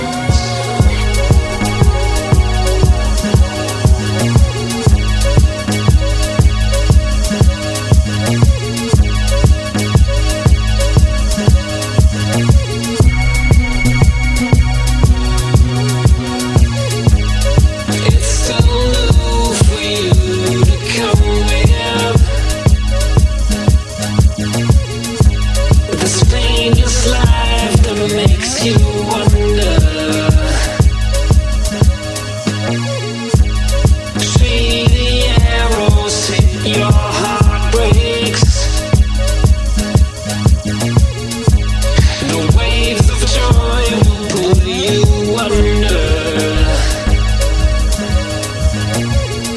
ghostly hymnals samples